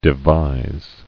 [de·vise]